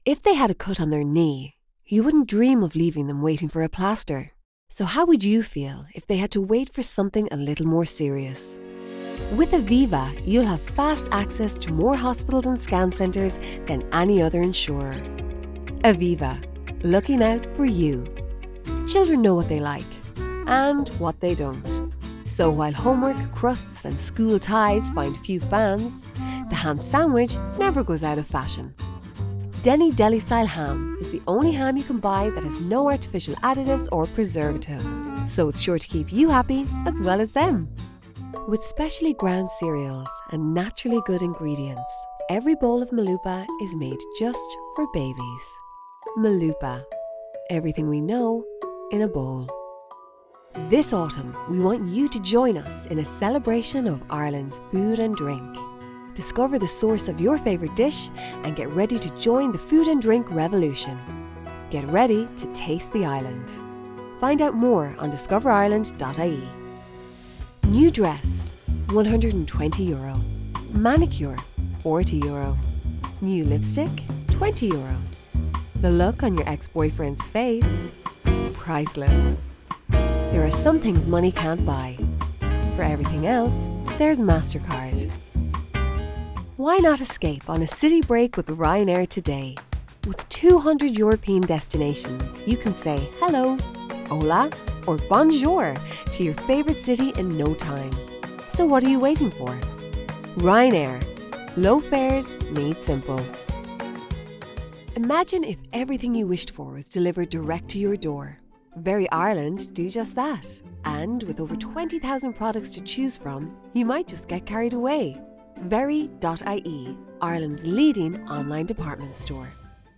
Female
Studio/Edit Booth - AKG SA 41/1
Irish neutral, West of Ireland, Soft
20s/30s, 30s/40s
Irish Neutral, Irish West